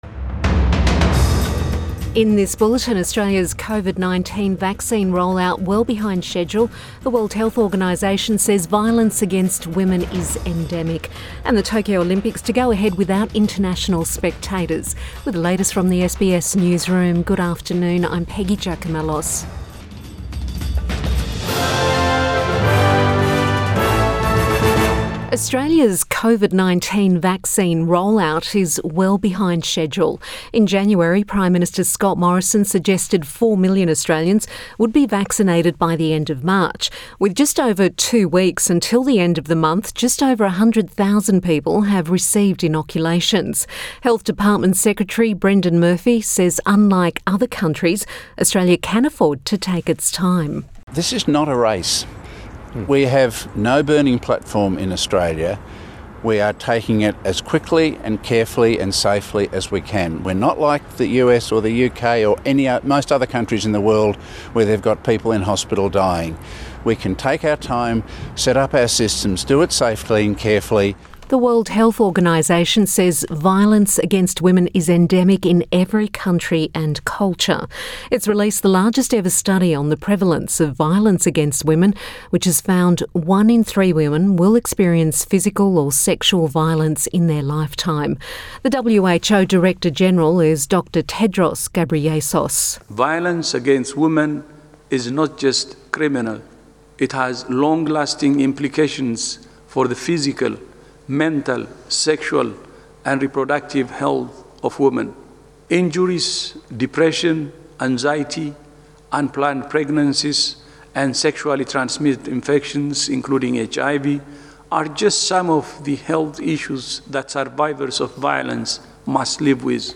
Midday bulletin 10 March 2021